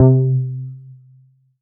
junobass.wav